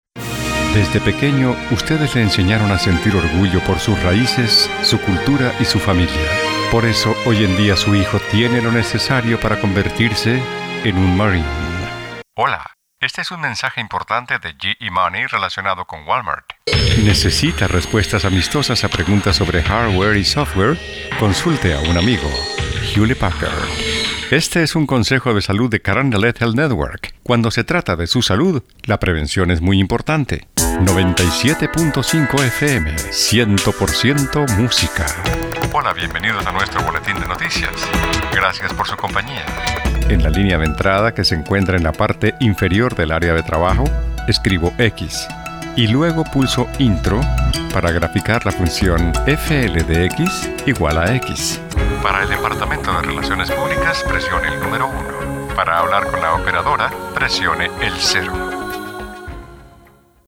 His elegant, multitalented and distinctive delivery is recognized as the very best in the industry.
spanischer Sprecher (Südamerika) voice-talent voice-over Colombian Native Locutor Neutro Colombiano
Sprechprobe: Sonstiges (Muttersprache):